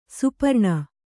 ♪ suparṇa